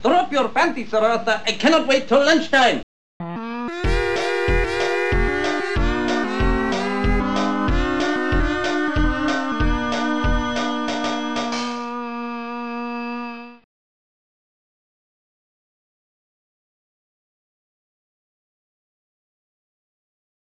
Digital Symphony Module  |  1994-05-15  |  62KB  |  2 channels  |  44,100 sample rate  |  20 seconds
Cymbal1
Cymbal2
BassDrum5